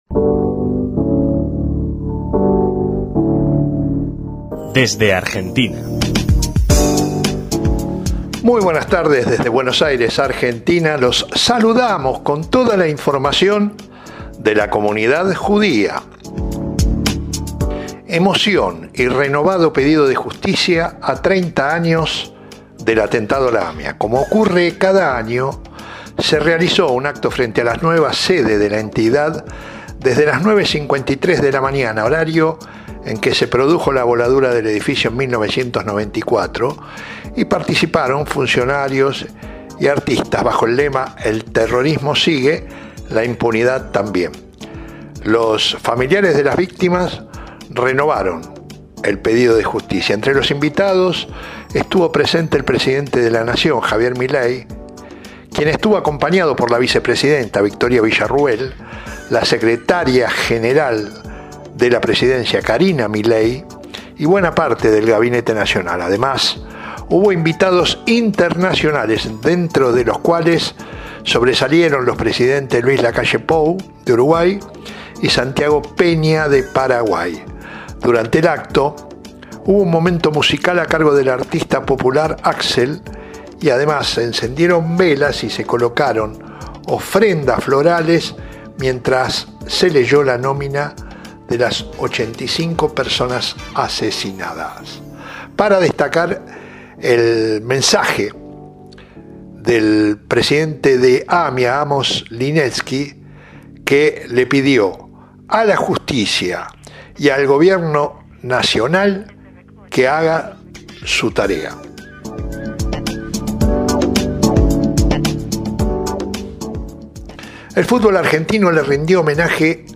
resumen busemanal